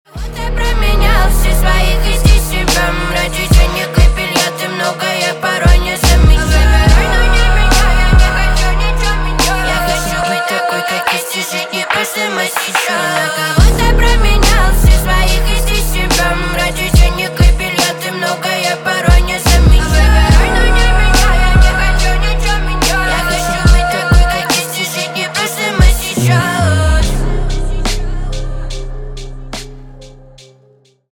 на русском грустные на бывшего